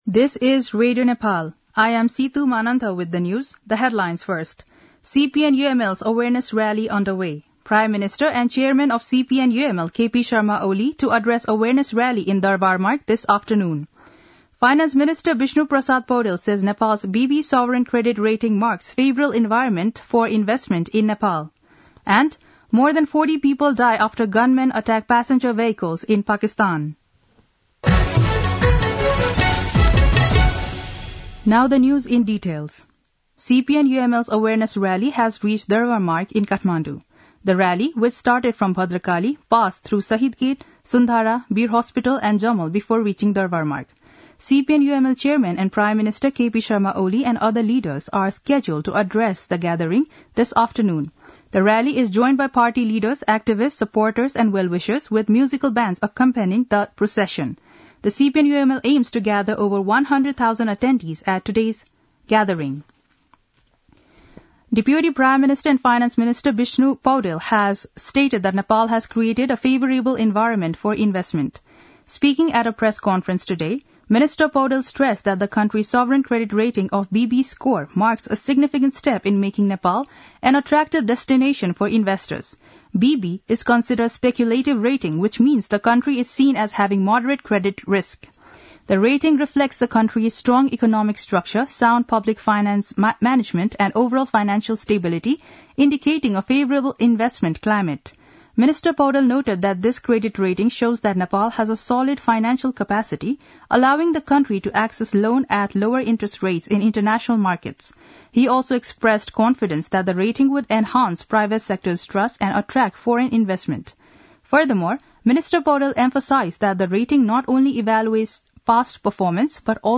दिउँसो २ बजेको अङ्ग्रेजी समाचार : ८ मंसिर , २०८१
2-pm-english-news-1-9.mp3